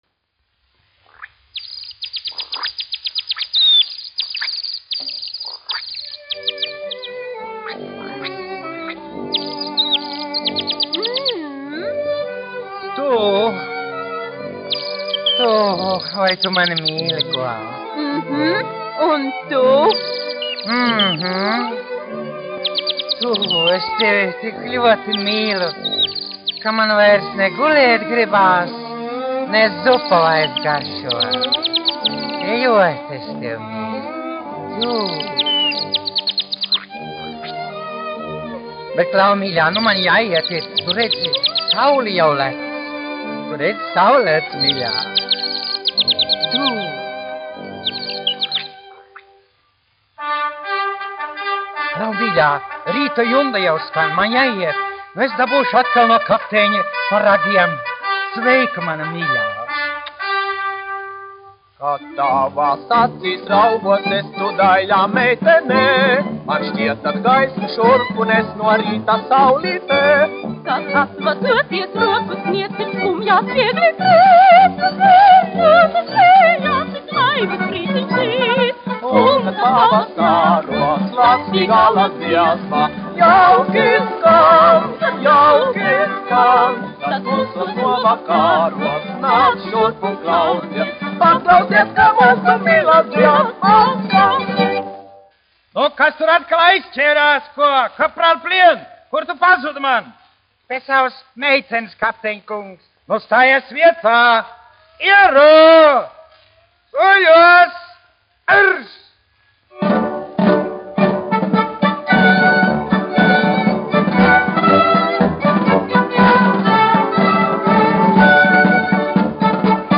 1 skpl. : analogs, 78 apgr/min, mono ; 25 cm
Humoristiskās dziesmas
Skaņuplate